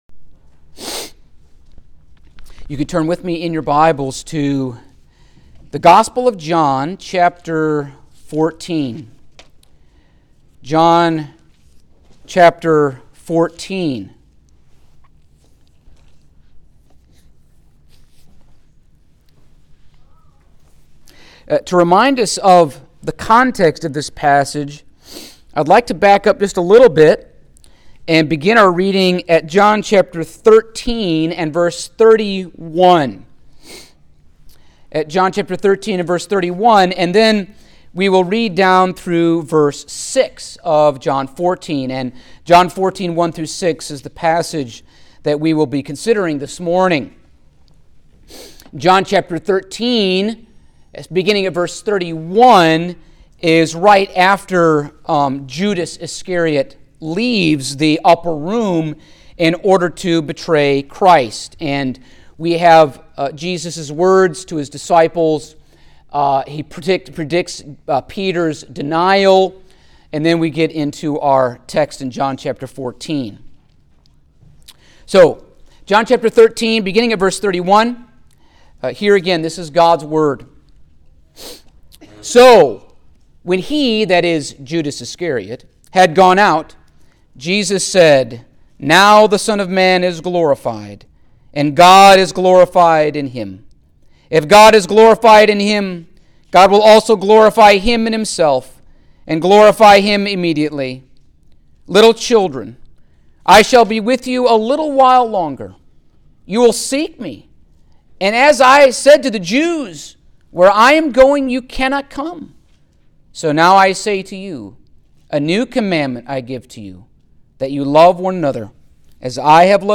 Passage: John 14:1-6 Service Type: Sunday Morning